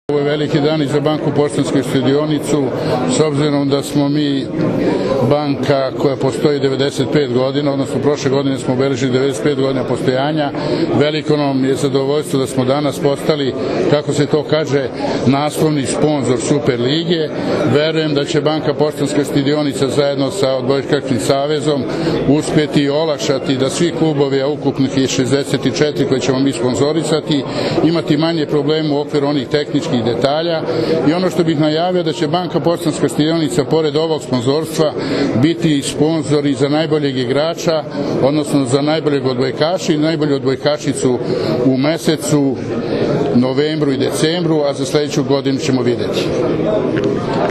Odbojkaški savez Srbije i Banka Poštanska štedionica potpisali su danas Ugovor o saradnji u konferencijskoj sali Turističke organizacije Srbije u Beogradu.
IZJAVA